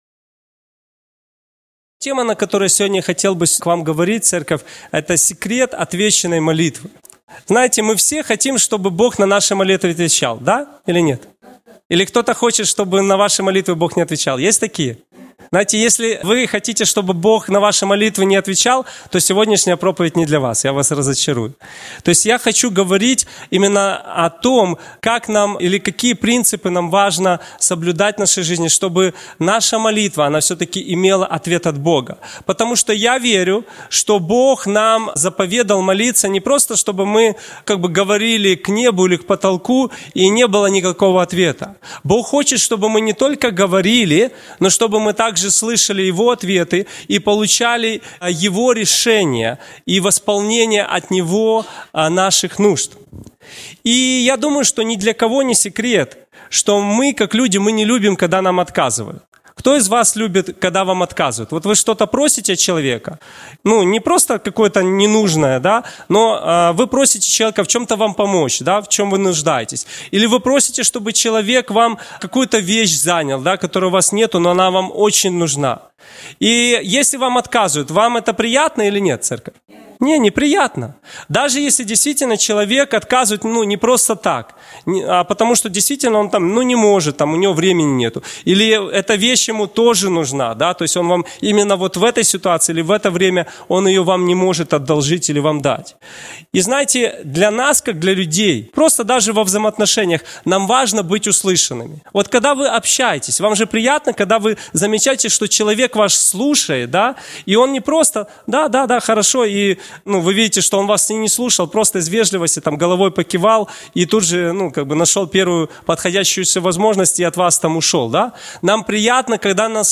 Если да, то тогда сегодняшняя проповедь не для вас. Сегодня я хотел бы говорить о том, как получить ответ на молитву.